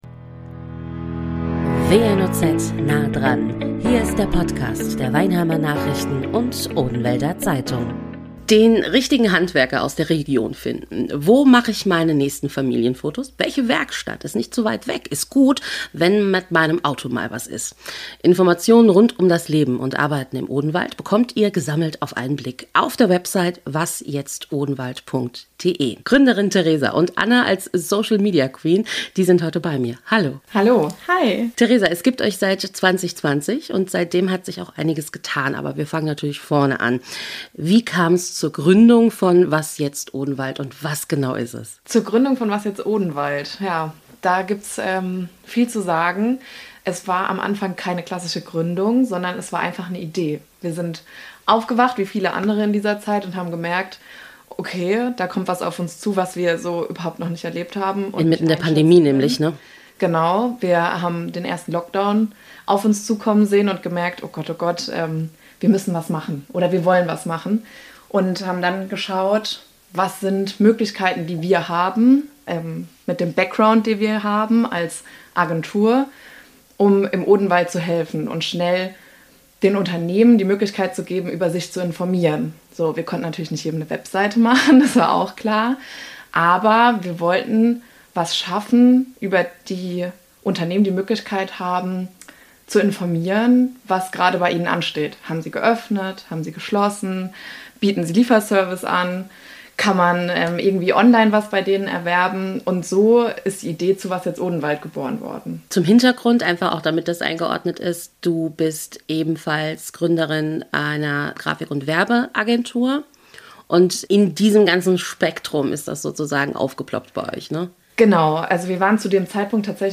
Studiogäste